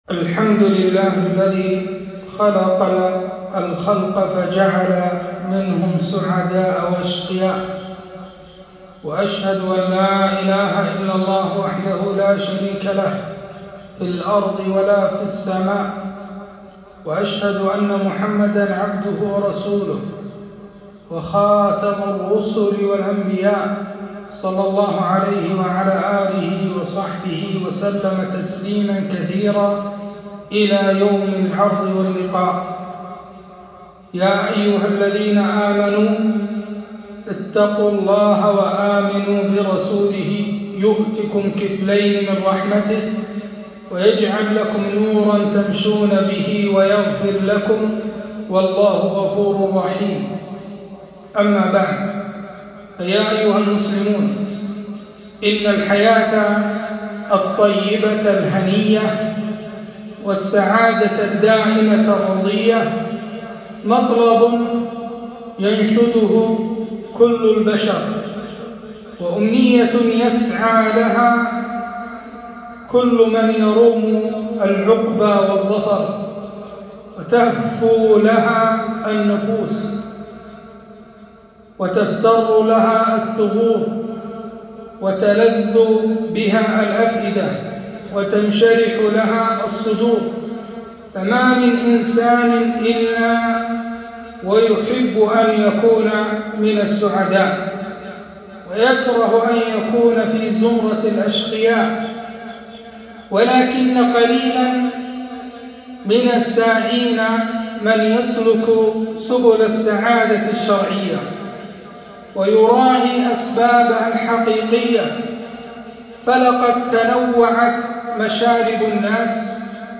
خطبة - حتى تكون أسعد الناس